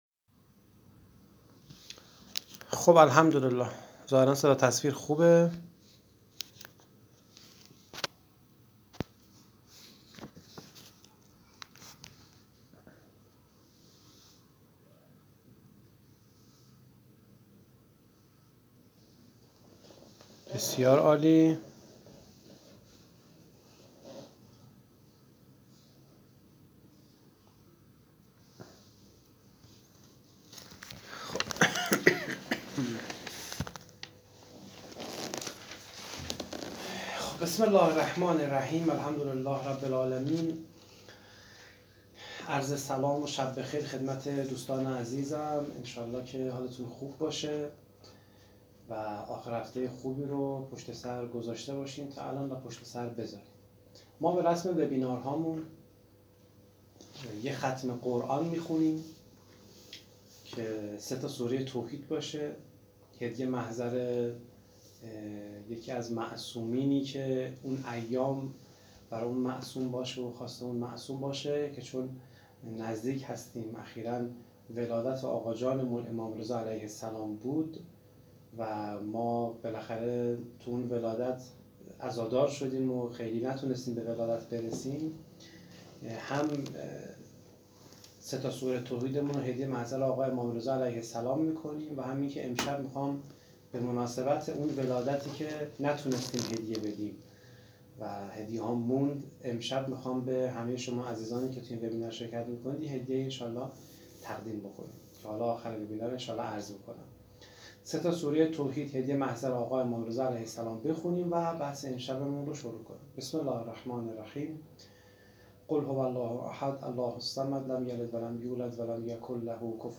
فایل صوتی وبینار تاثیر حال خوب بر زندگی و بندگی